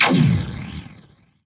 LaserGun_111.wav